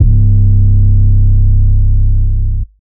BWB WAV 7 808 (3).wav